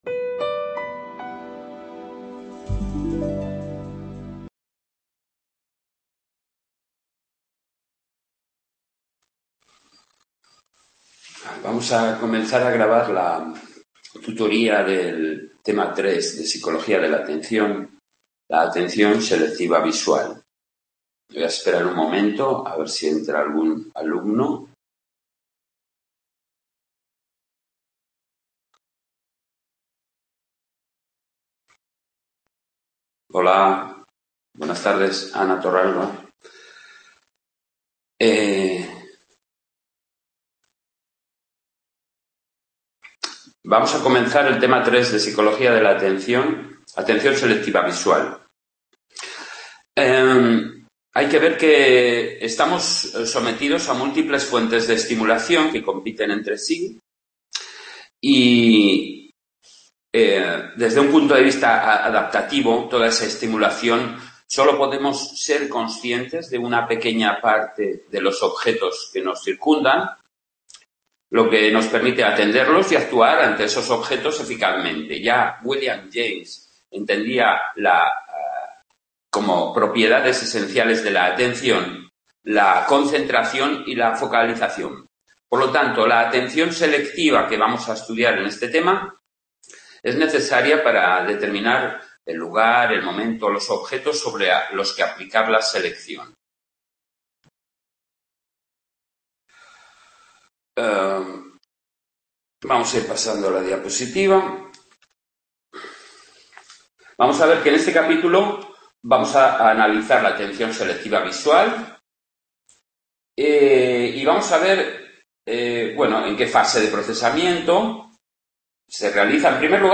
Grabación desde casa